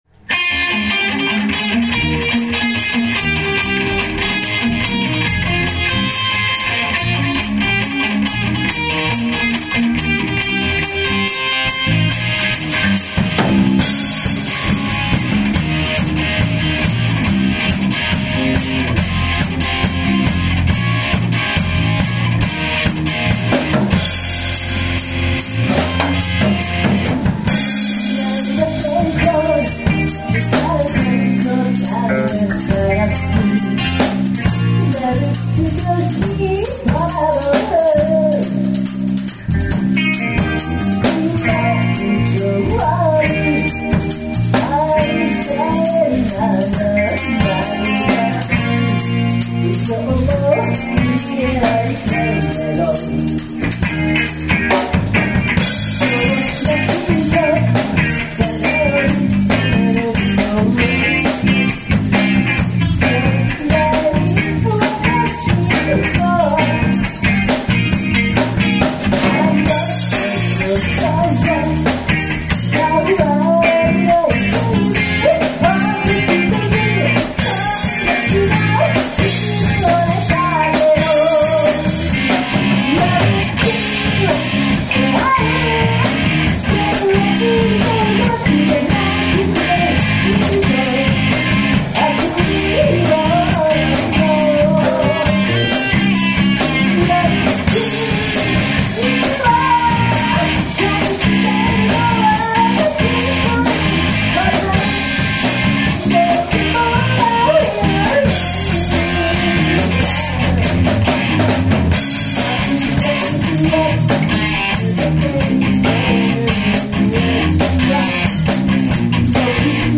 DEMO　CD
2008年5月バージョンも移植しました。（配布デモCDとは別音源です）